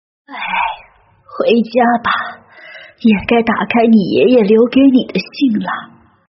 分段配音